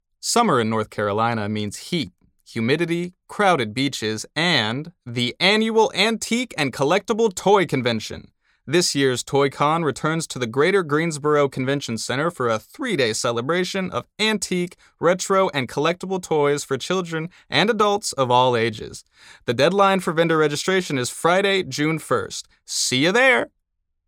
Male
Yng Adult (18-29)
Radio Commercials
Words that describe my voice are Versatile, Calm, Natural.